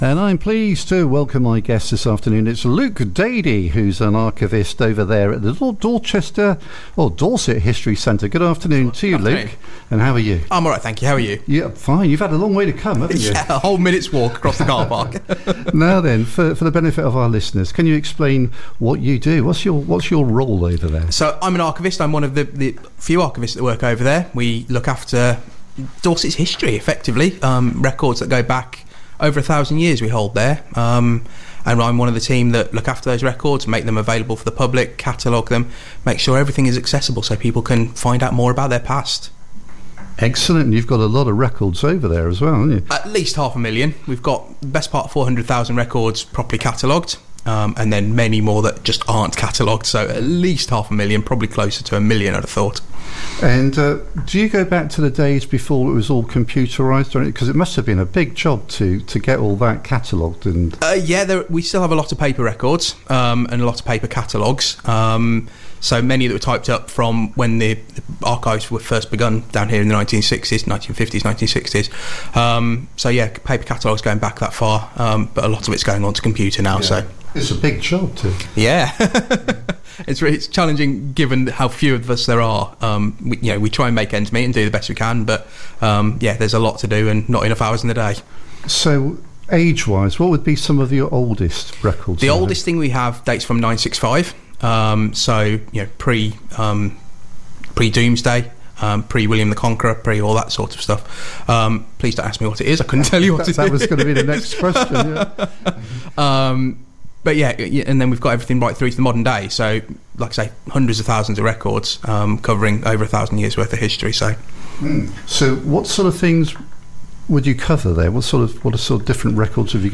in the KeeP 106 studio